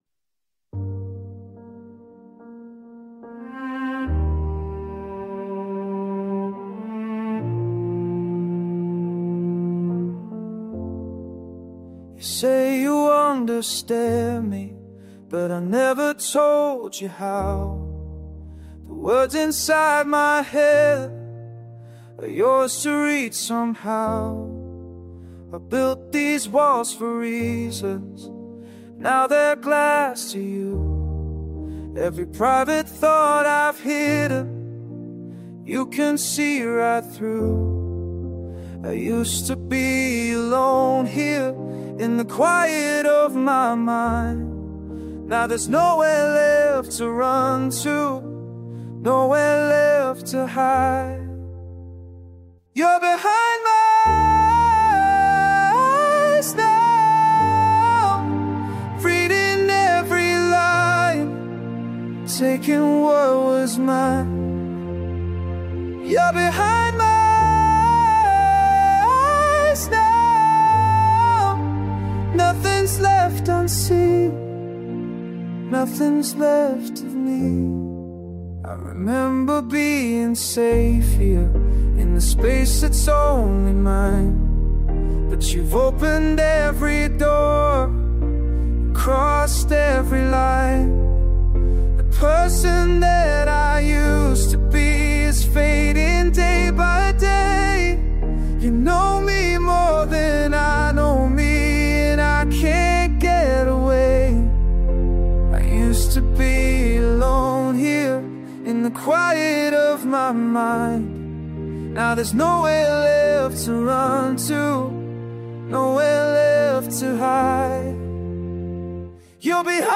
The simple Piano music followed by violin vibrations